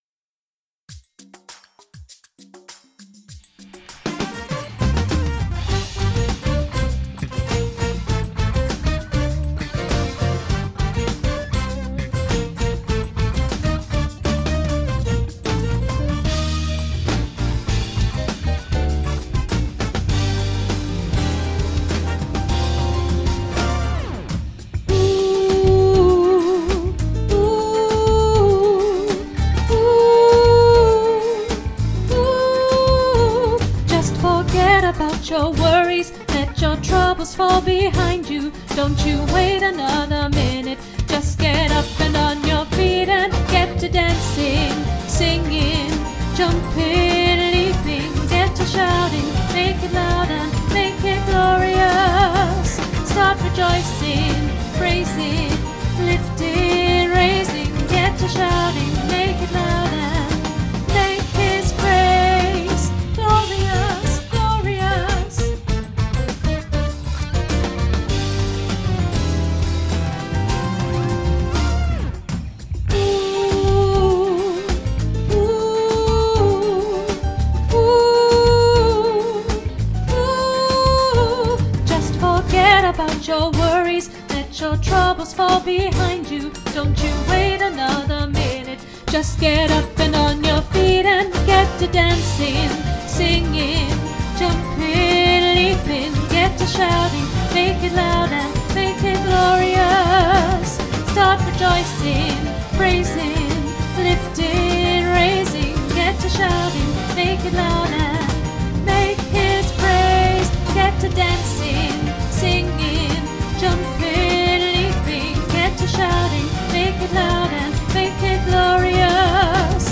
Glorious-Alto.mp3